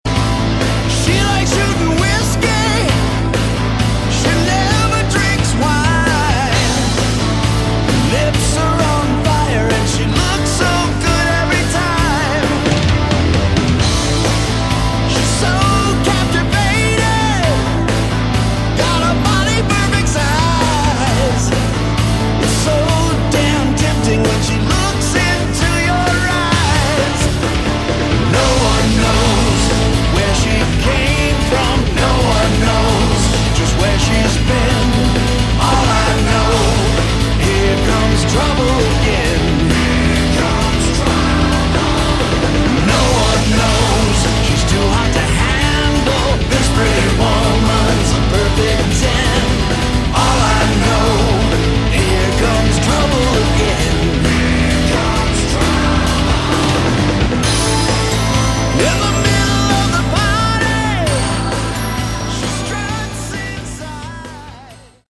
Category: Hard Rock
lead and backing vocals, lead and rhythm guitars
bass, backing vocals, keyboards, drums, programming